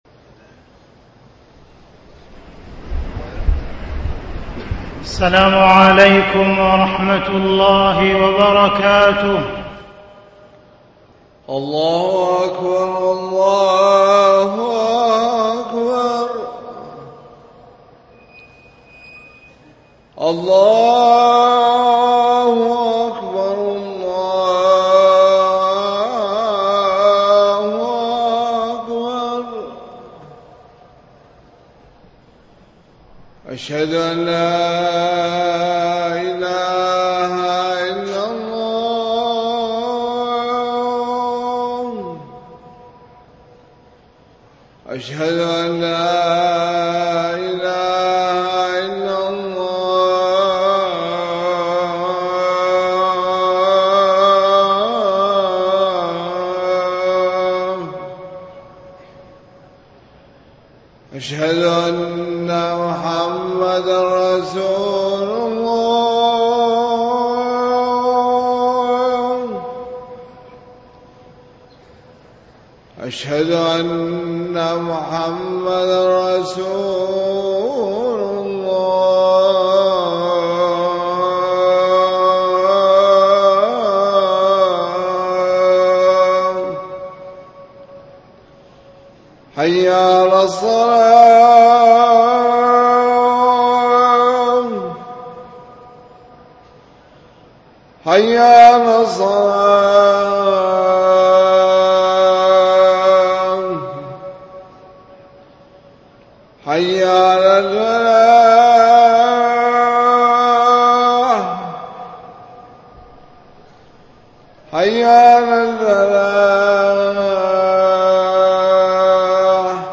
Hajj | Audio Bayans | All Ceylon Muslim Youth Community | Addalaichenai
Makkah, Masjidhul Haraam